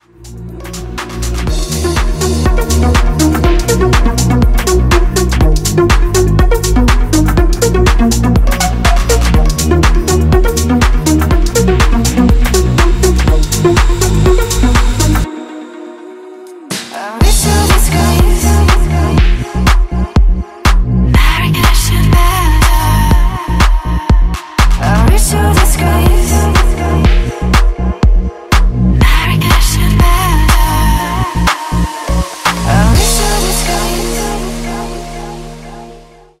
• Качество: 192, Stereo
deep house
dance
спокойные
чувственные
красивый женский голос